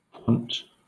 Ääntäminen
Etsitylle sanalle löytyi useampi kirjoitusasu: quant Quant Ääntäminen Southern England: IPA : /kwɒnt/ Haettu sana löytyi näillä lähdekielillä: englanti Käännöksiä ei löytynyt valitulle kohdekielelle.